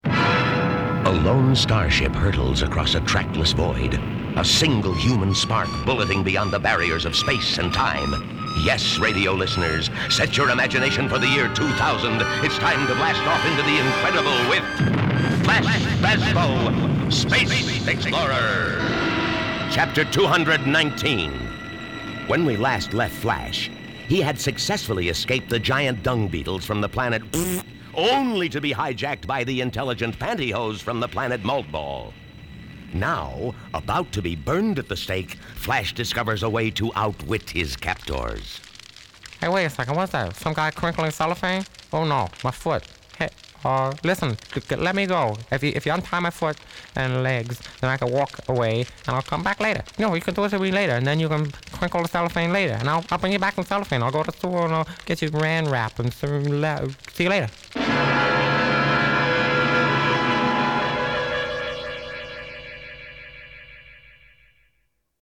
Here's an example of Guest's early work: Flash Bazbo, Space Explorer, one of the offbeat "character sketches" that he created for the NLRH: